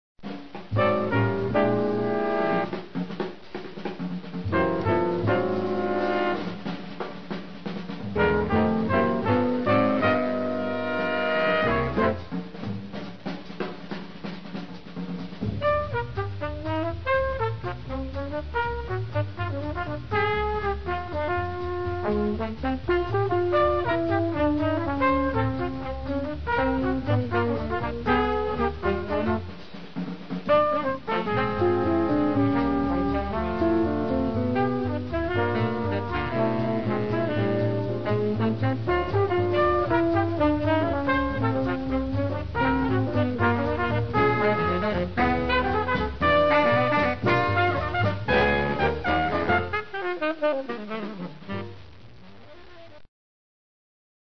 Voicing: Combo 7+